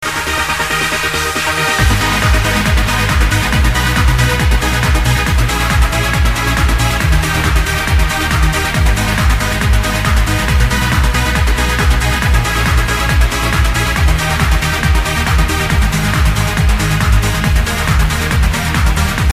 Trance & Progressive from 1999-2004 Listen here https